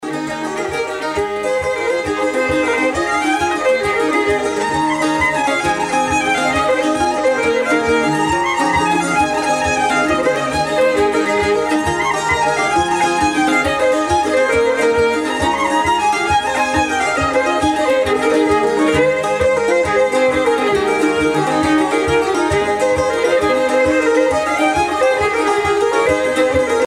danse : jig
Pièce musicale éditée